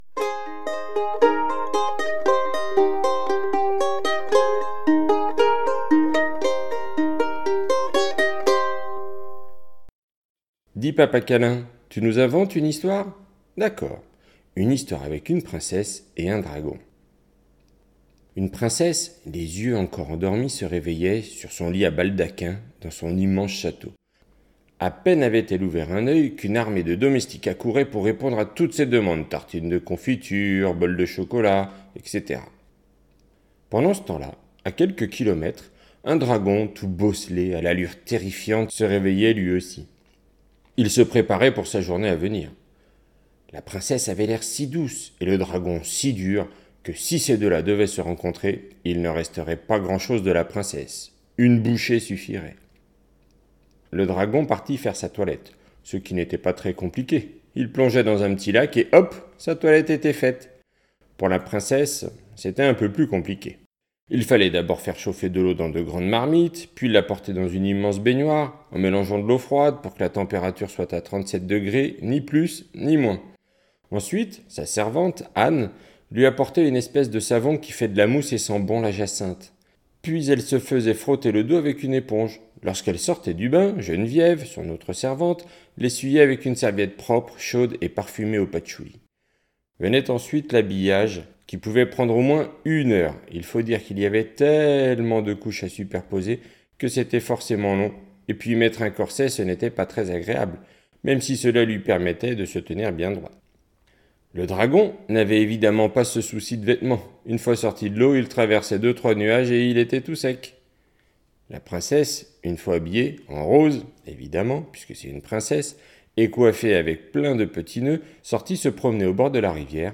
Livre audio